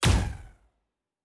pgs/Assets/Audio/Sci-Fi Sounds/Weapons/Weapon 01 Shoot 2.wav at master
Weapon 01 Shoot 2.wav